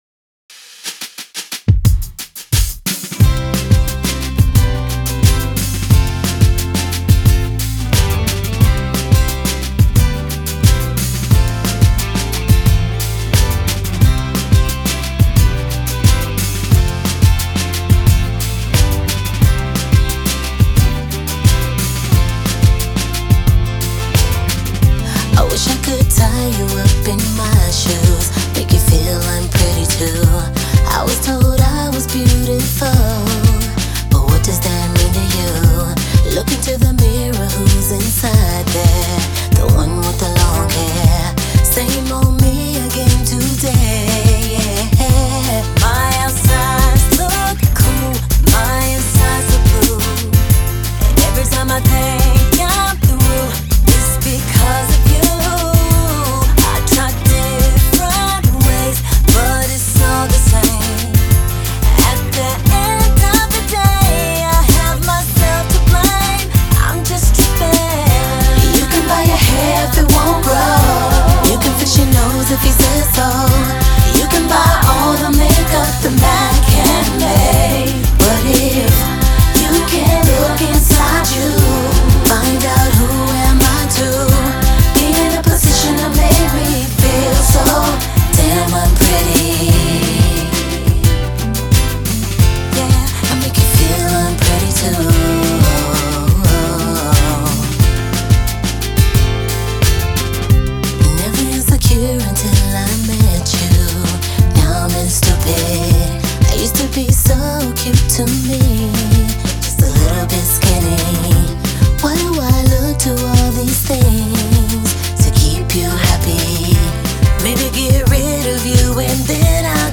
伝説のガールズグループの楽曲です。心地よいトラックに彼女たちのハーモニーがたまりまん。